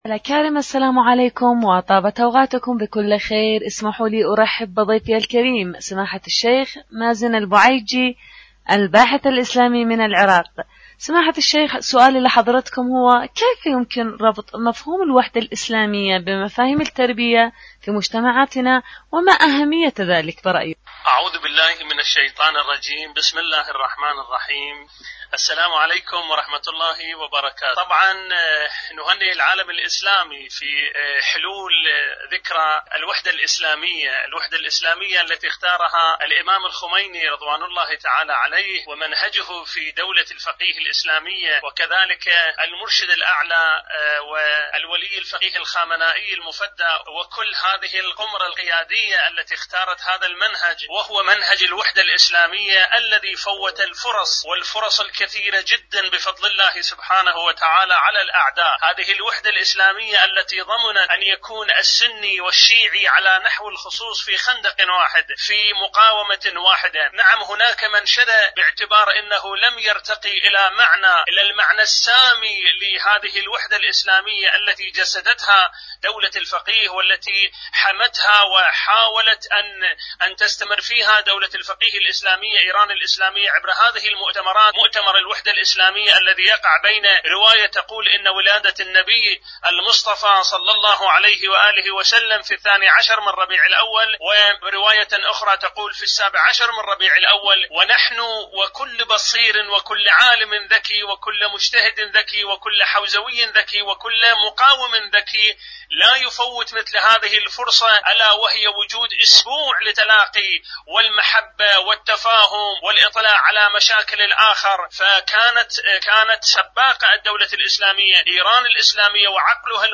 الوحدة الإسلامية ومشروع التربية.. مقابلة
إذاعة طهران-معكم على الهواء